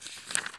x_enchanting_scroll.3.ogg